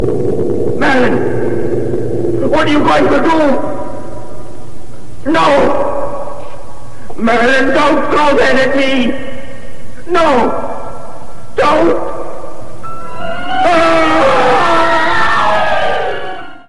We also get audio of Merlin doing…